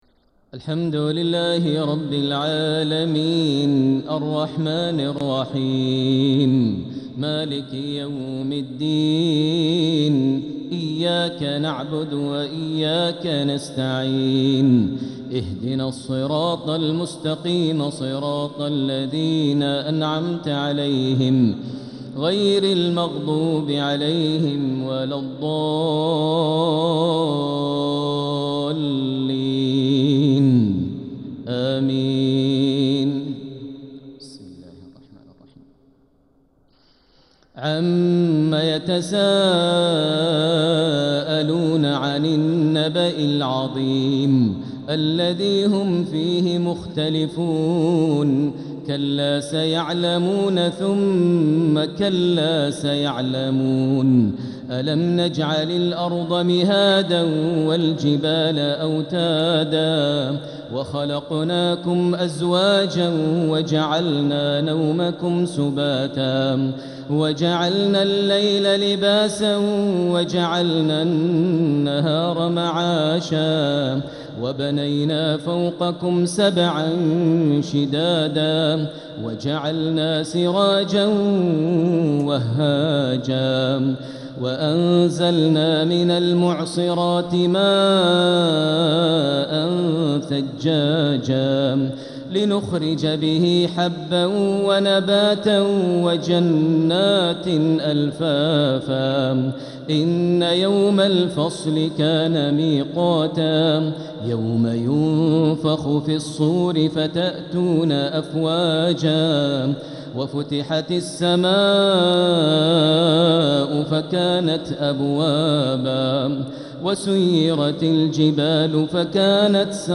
تلاوة من سورة النبأ الى سورة المطففين | تهجد ليلة 28 رمضان 1446هـ > تراويح 1446 هـ > التراويح - تلاوات ماهر المعيقلي